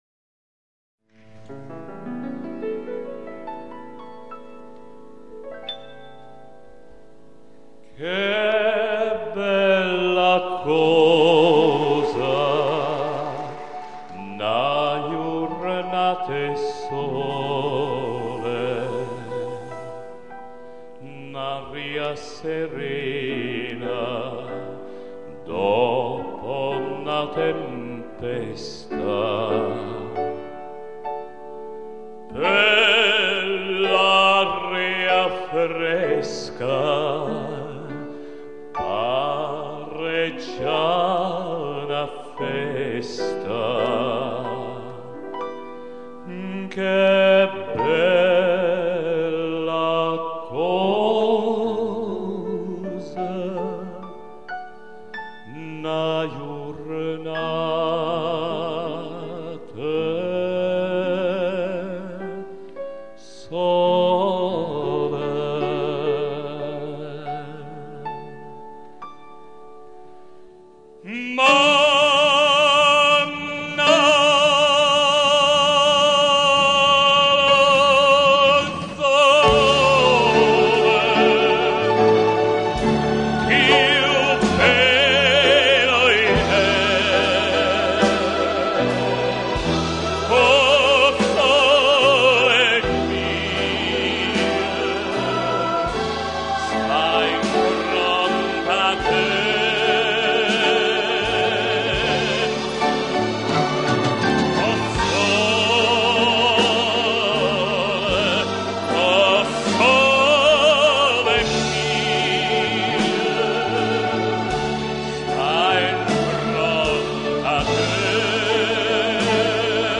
Italian songs.
live concert